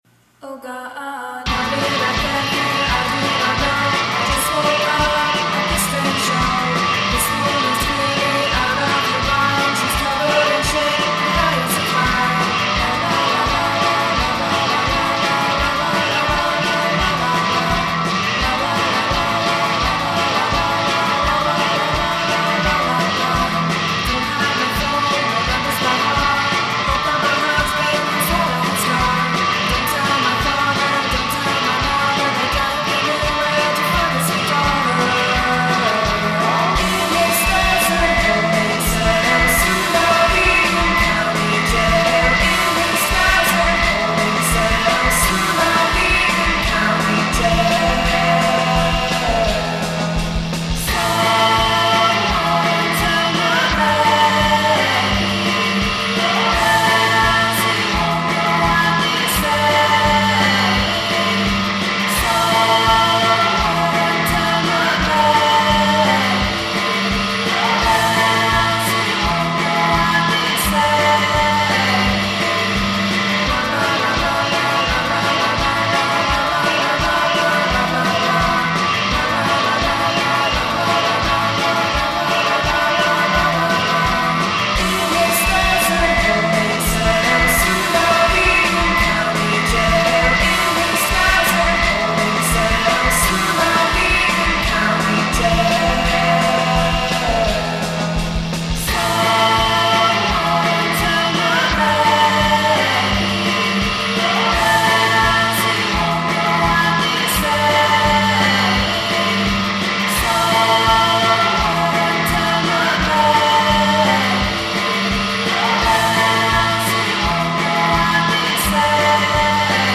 "second-wave shoe gaze."